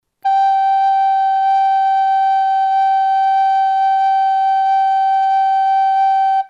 A nota SOL.